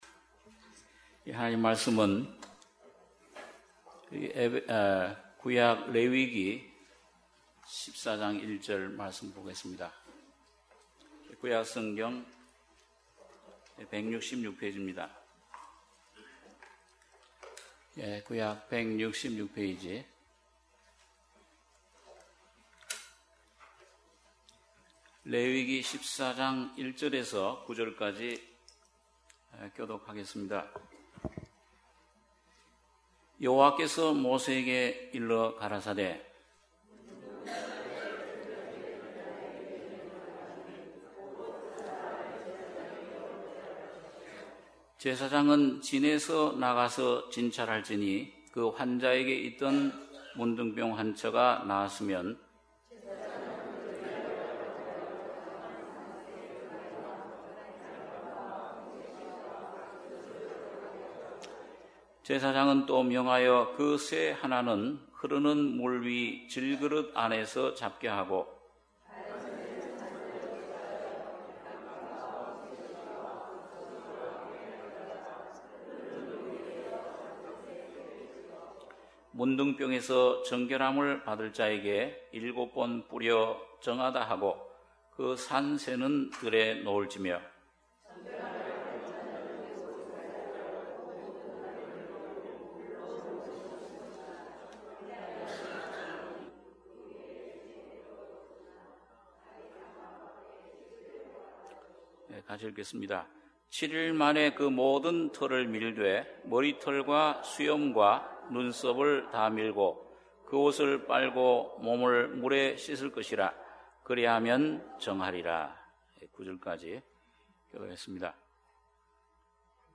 주일예배 - 레위기 14장 1절-9절